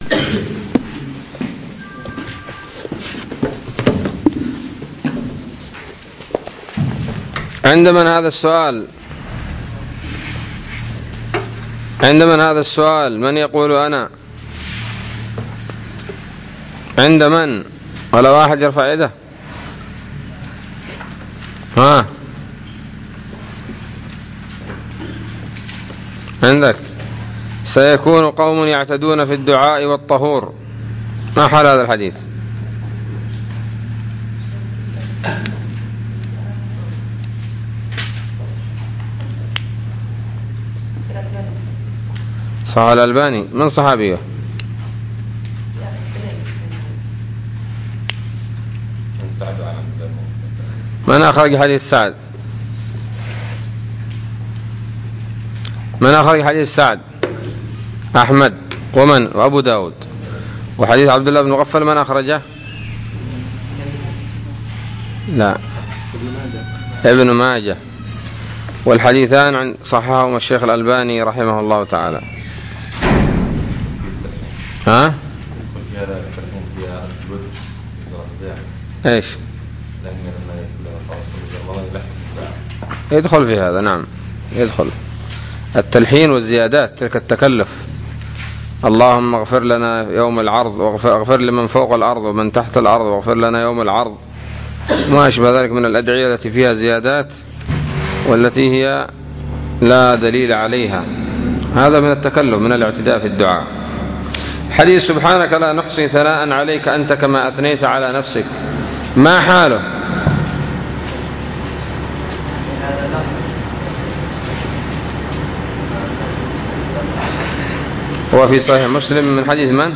الدرس الواحد والثلاثون من شرح العقيدة الواسطية